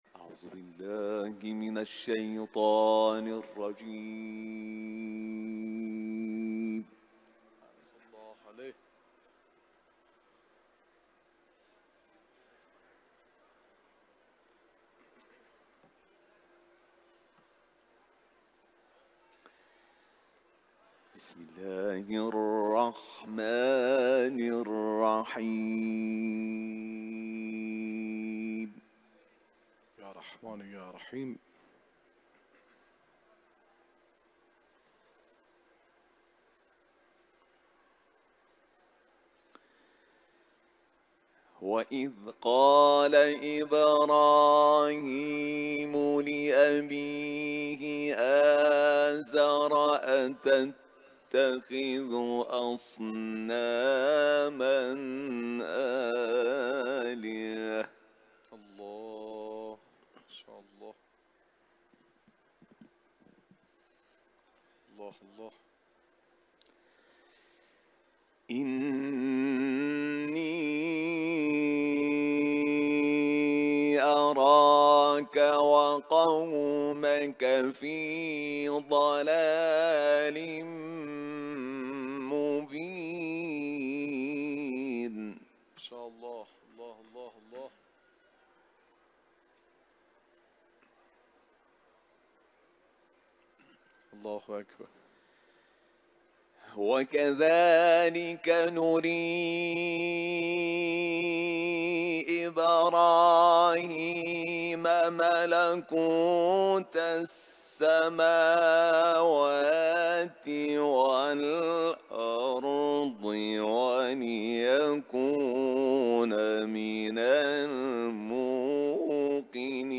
yang dilakukan dalam Perhimpunan cinta Al-Quran di makam suci Imam Redha (as)